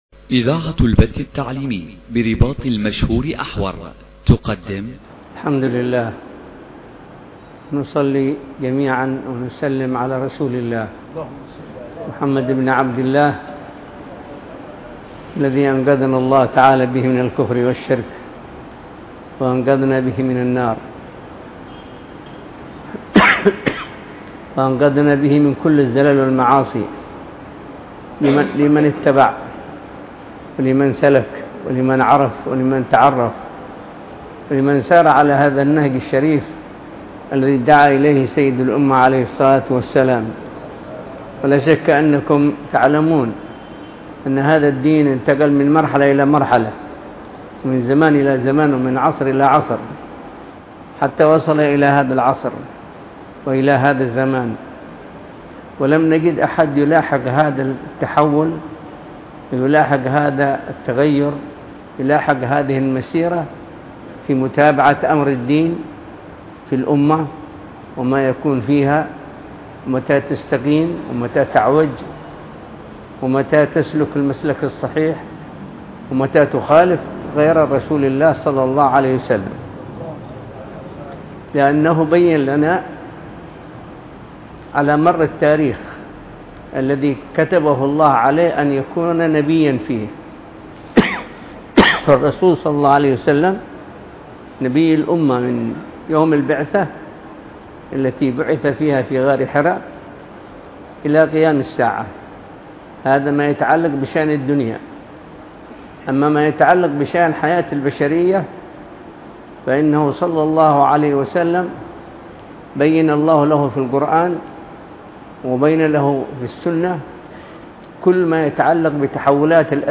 محاضرة
مساء يوم الاحد ١١جمادي الاخره ١٤٤٢ هـ برباط بحر النور بمنطقة فوة بالمكلا في محافظة حضرموت